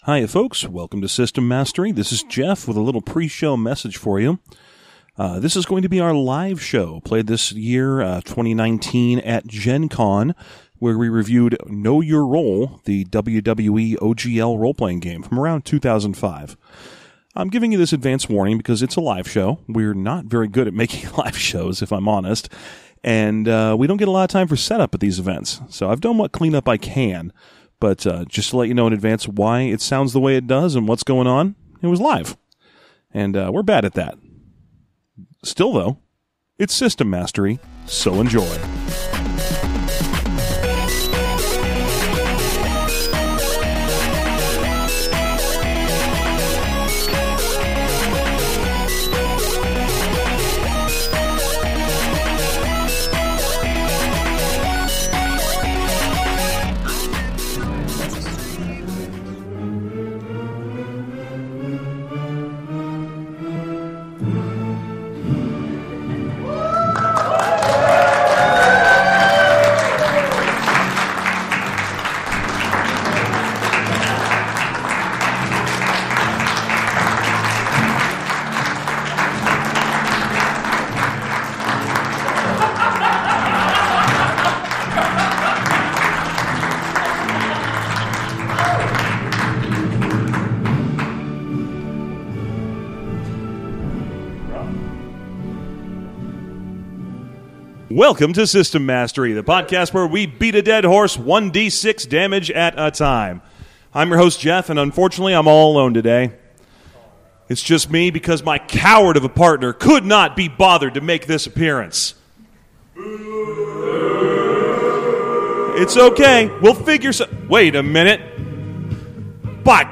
Live from GenCon 2019, it’s System Mastery! We’re covering the greatest entertainment to come out of the squared circle in 2005, actual wrestling!